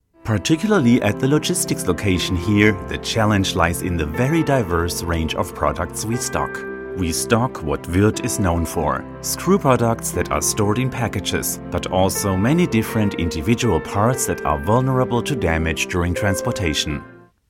Voice-Over
Corporate Video - English